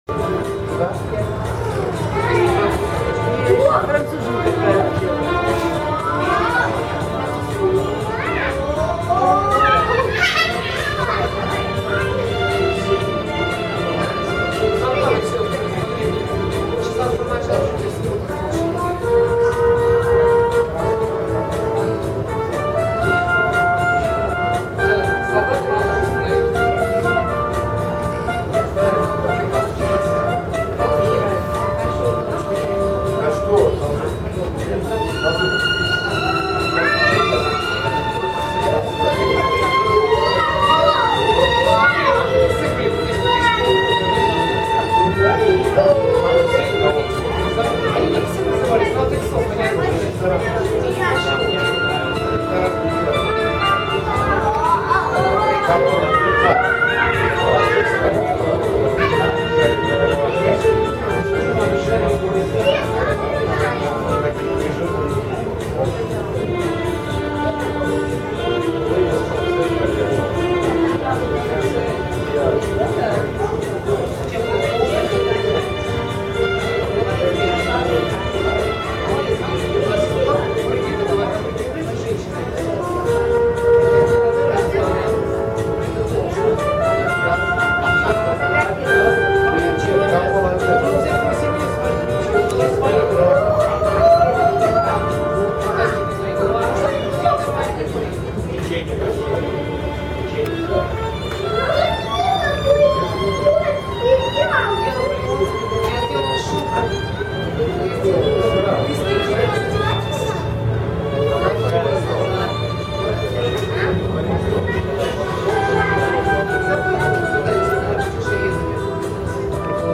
исполняет её возможно оркестр под упр.